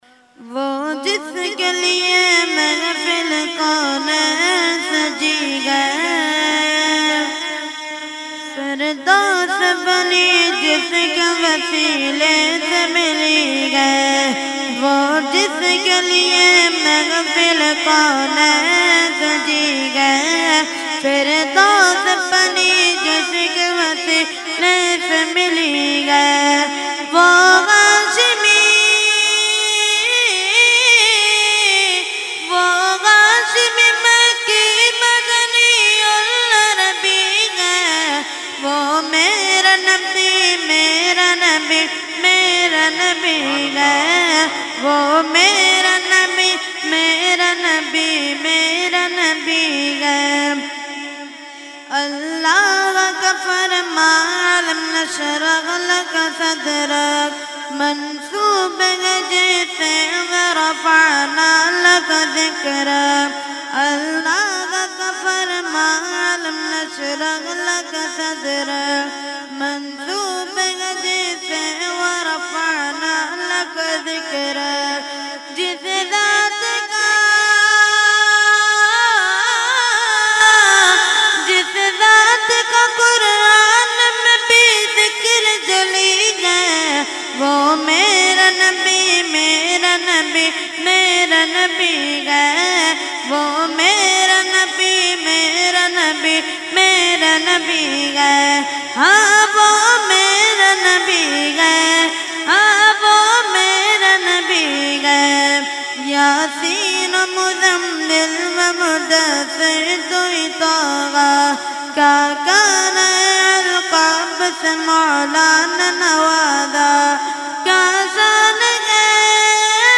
held on 21,22,23 December 2021 at Dargah Alia Ashrafia Ashrafabad Firdous Colony Gulbahar Karachi.
Category : Naat | Language : UrduEvent : Urs Qutbe Rabbani 2021-2